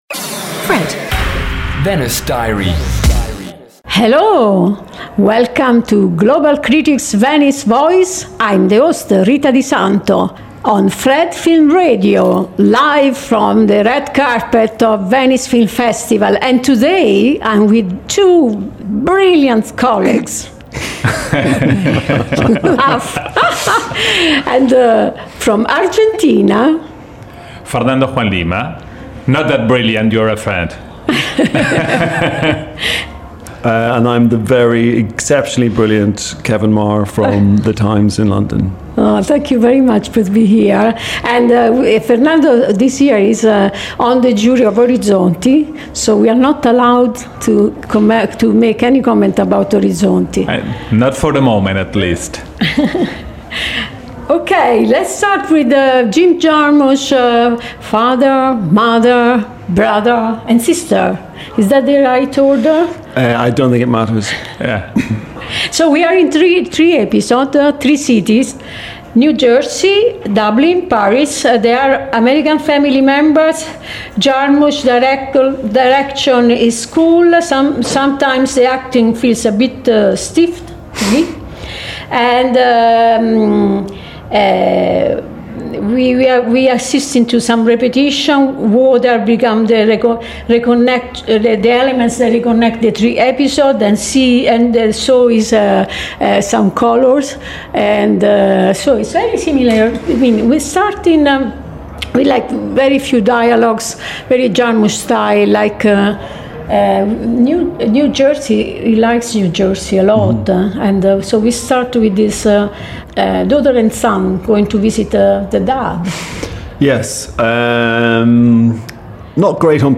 Each episode takes listeners inside the Festival with exclusive and thoughtful conversations with leading international film critics, and in-depth analysis of the year’s most anticipated films.